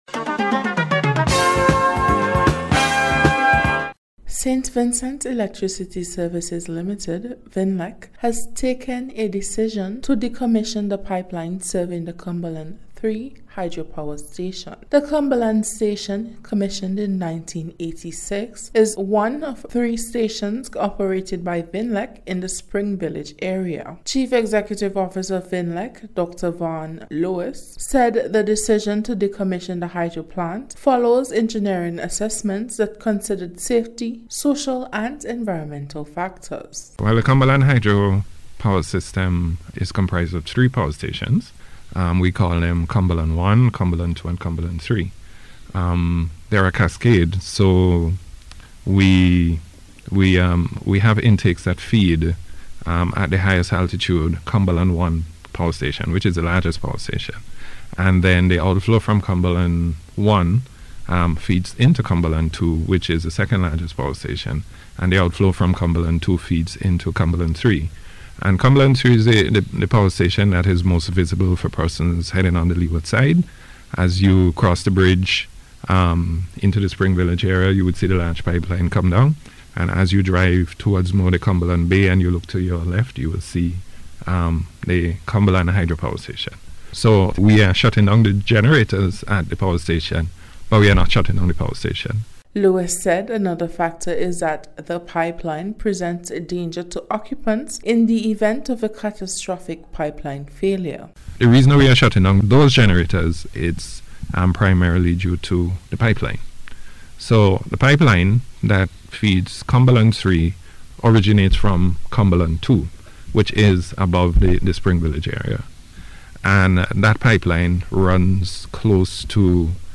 VINLEC-HYDROPOWER-REPORT.mp3